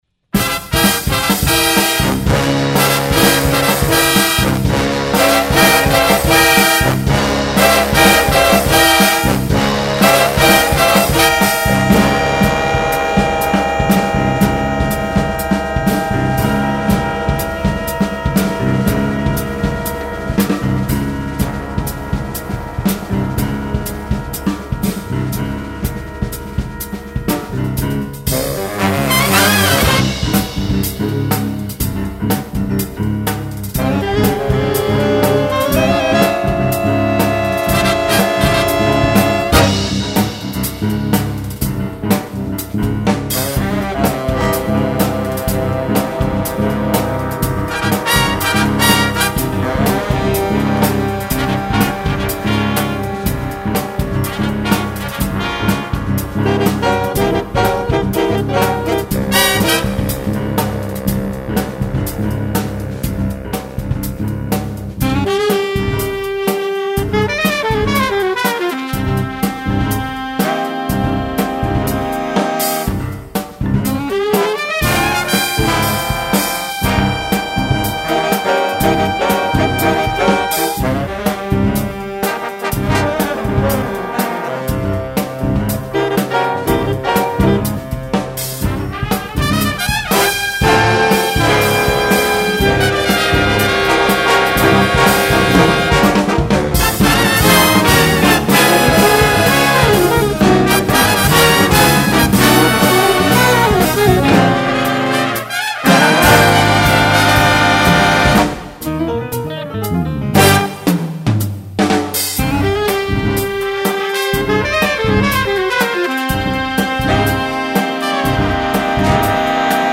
Voicing: Jazz Ensemble